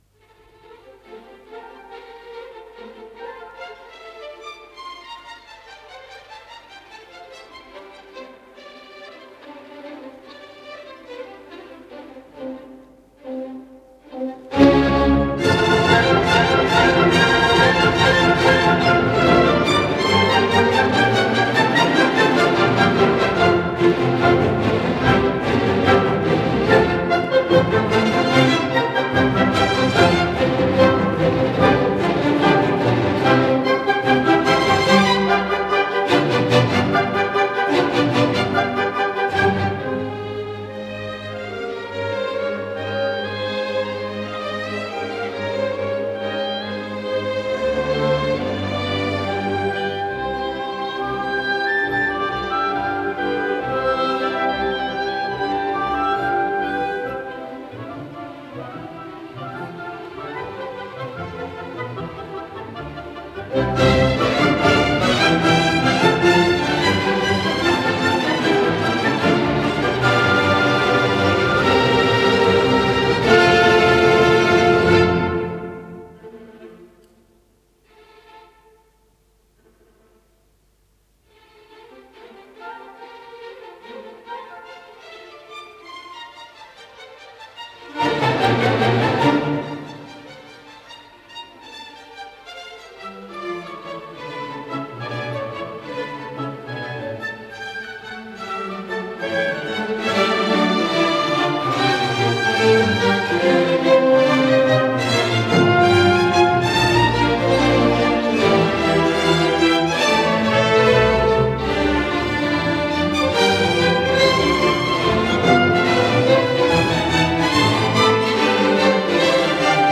с профессиональной магнитной ленты
Фа мажор.
Аллегро виваче
ИсполнителиСимфонический оркестр Московской государственной филармонии
Дирижёр - Рудольф Баршай
ВариантДубль моно